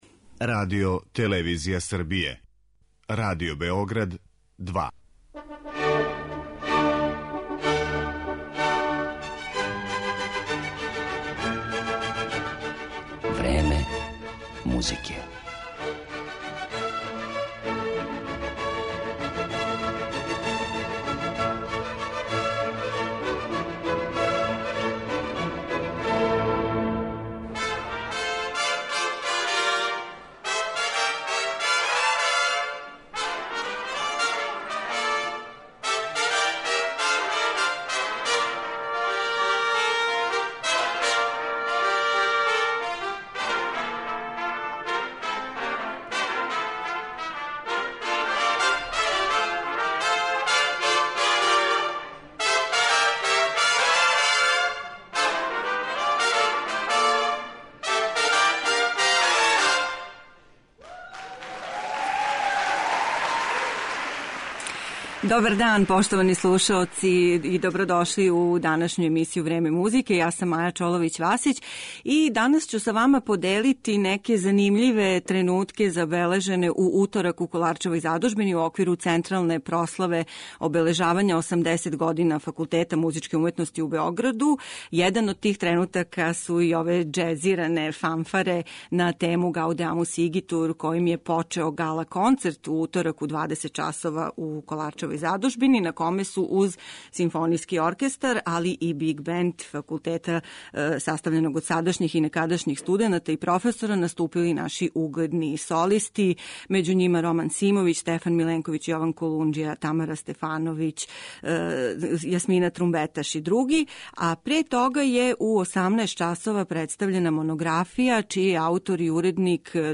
У Kоларчевој задужбини је у уторак 21. новембра одржана централна прослава 80 година Факултета музичке уметности у Београду.